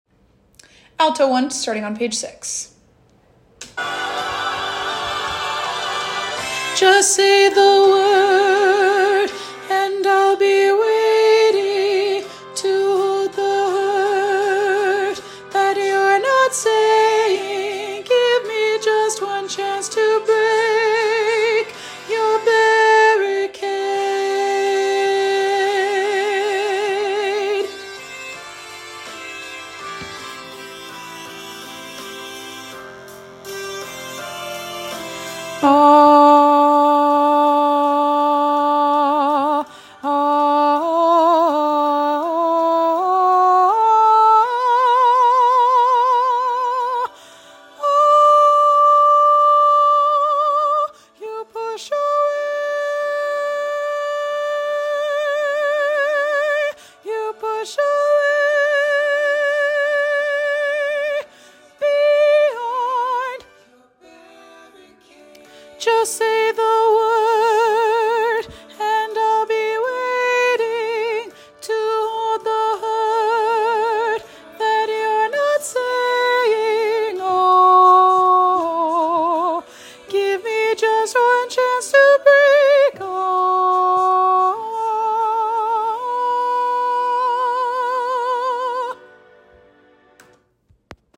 with lush harmonies, tall chords, and an epic conclusion.
Alto 1